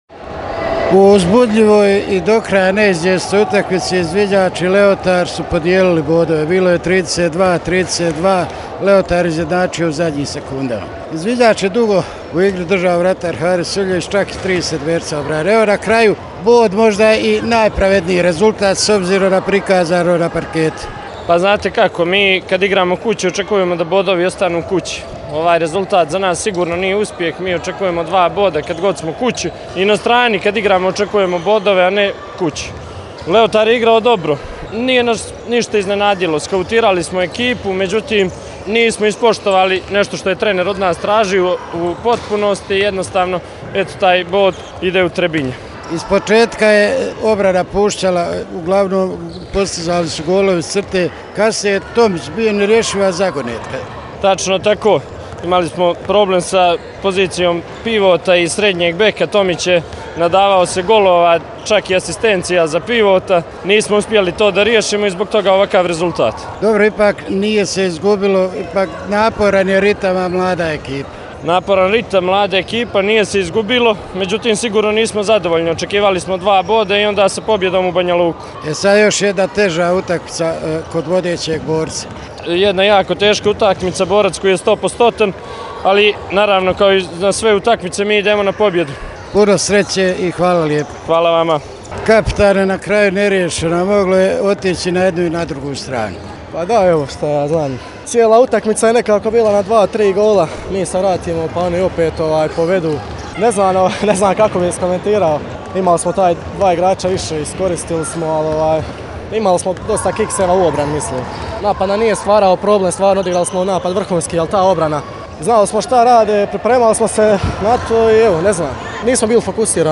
izv-leotar-post-game.mp3